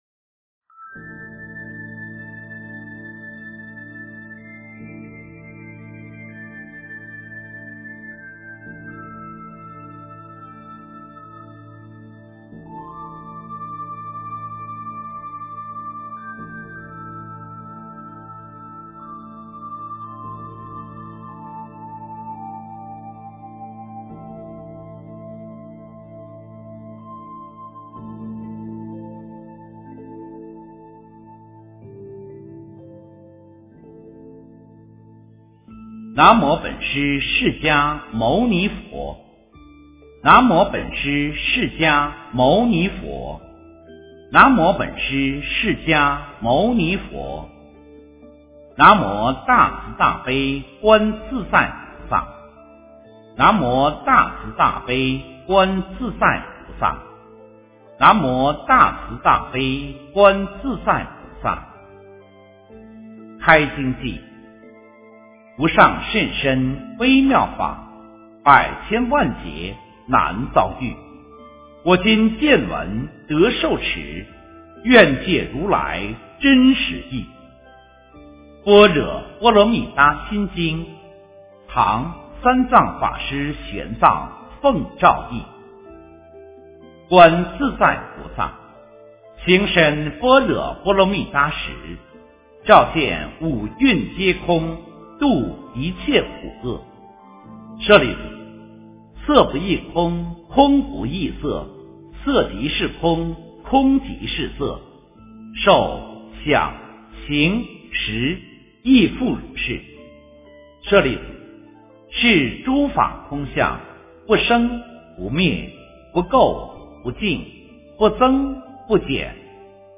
心经-读诵
诵经